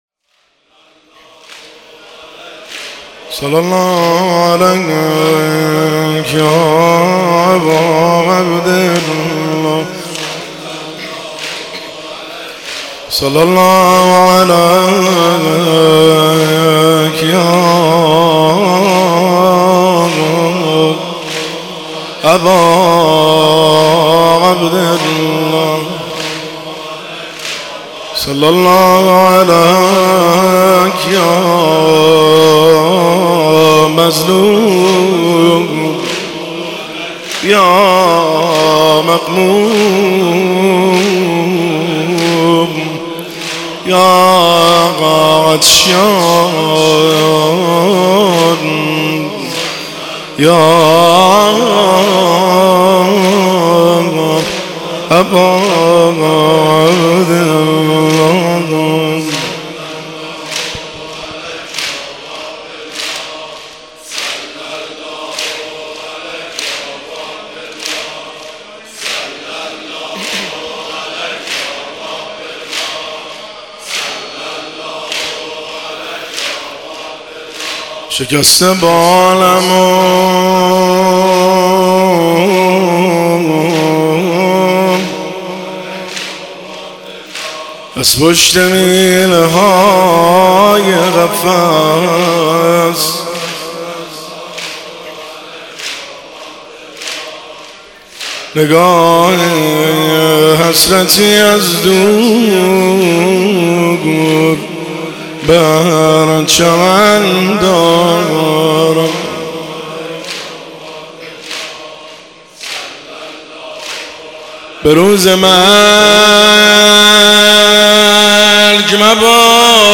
جلسه هفتگی 31 اردیبهشت 1404